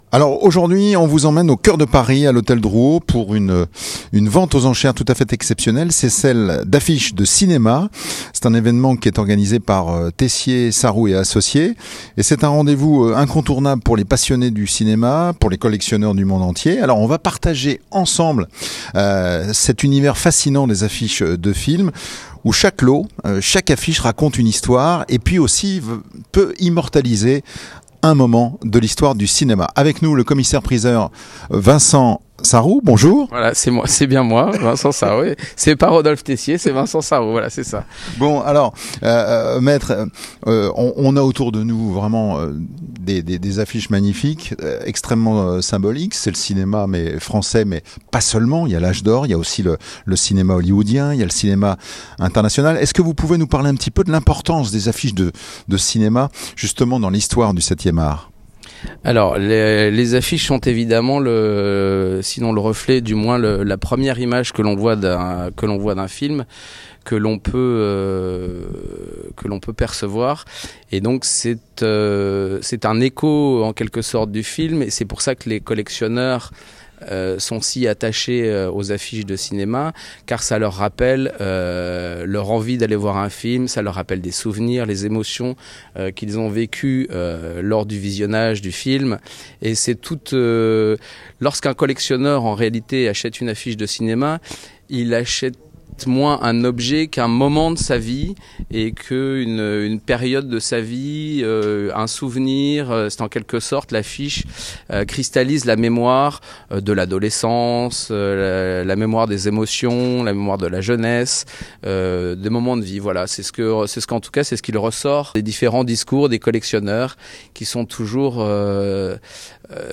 Paris, Hôtel Drouot, jeudi 24 avril 2025, Il est 09h, et déjà les collectionneurs se pressent dans la salle 9.
Une journée rythmée par les coups de marteau, les souvenirs de spectateurs et la passion palpable pour ces morceaux de papier qui valent parfois de l’or.